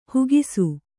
♪ hugisu